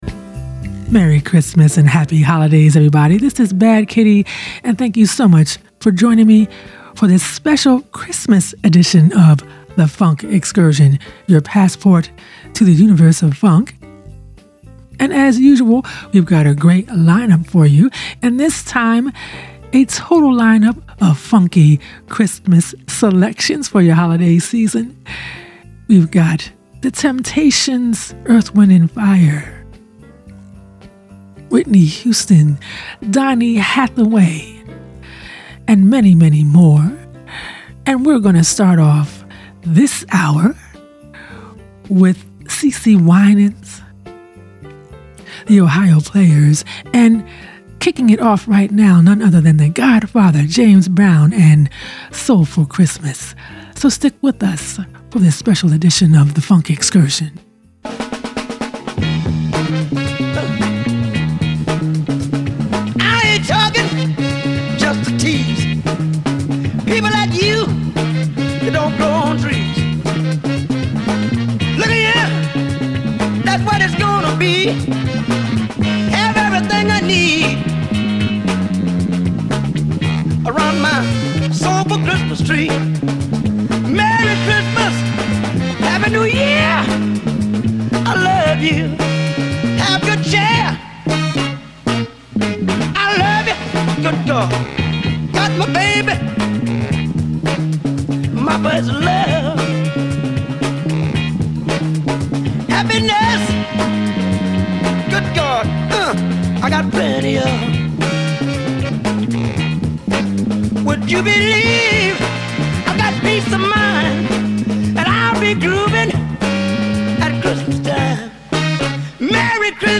Enjoy this mix of Christmas Funk